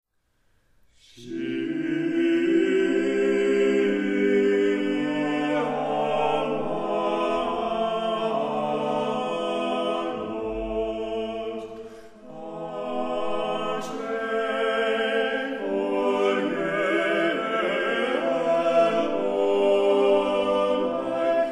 ensch dec 2008 CD: Live recordings of 3 concertsWeesp, Enschede and Amsterdam 2008/2009